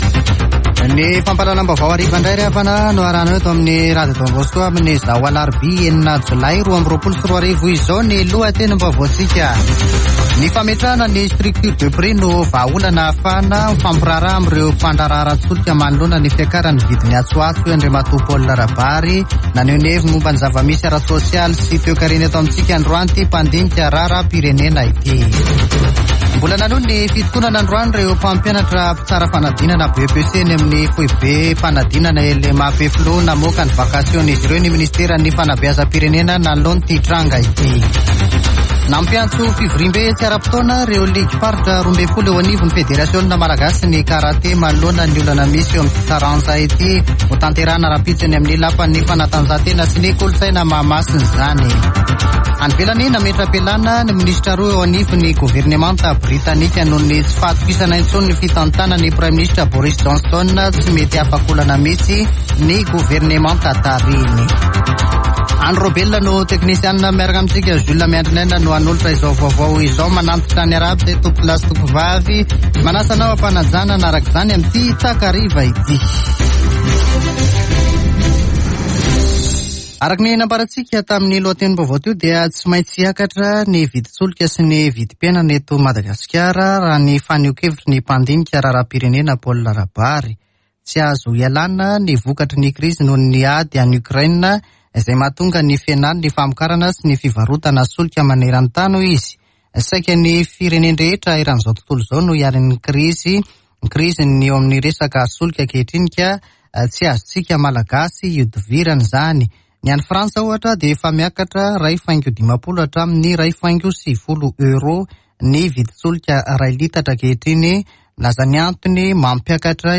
[Vaovao hariva] Alarobia 6 jolay 2022